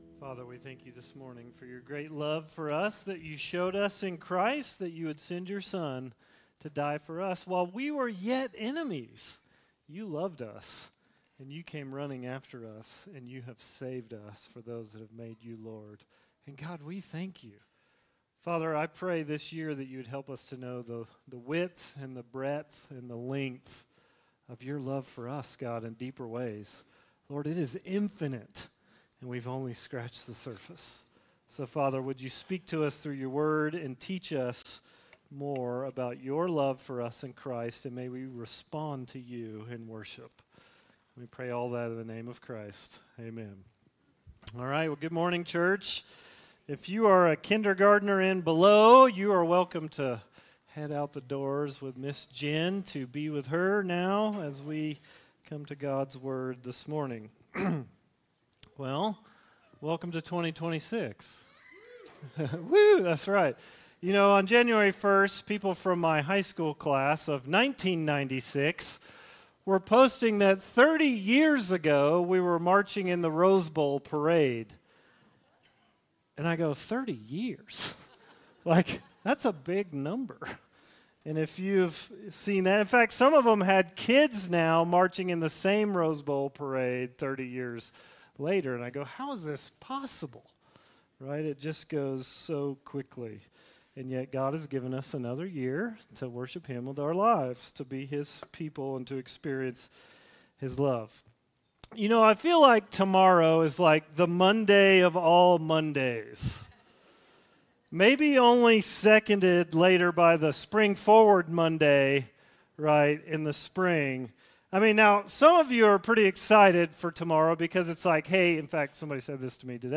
Worship Listen Sermon On the first Sunday of 2026 , we will start a short four week series – A Light in the Darkness.